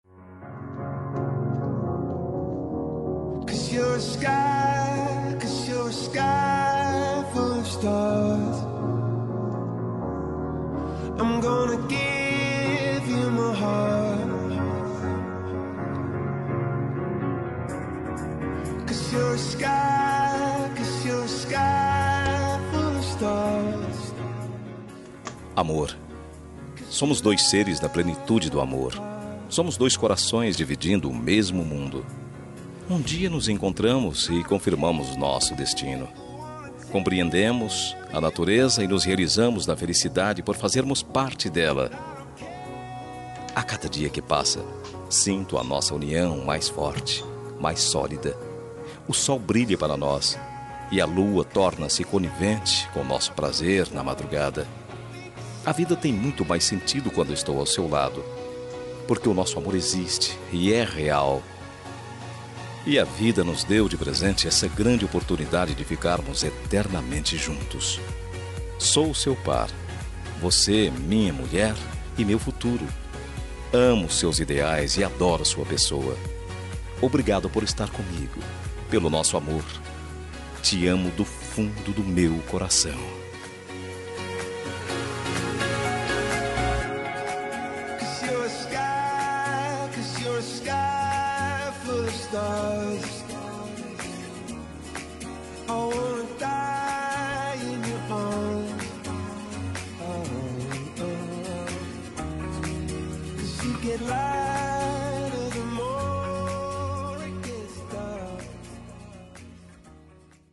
Romântica para Esposa – Voz Masculina – Cód: 6724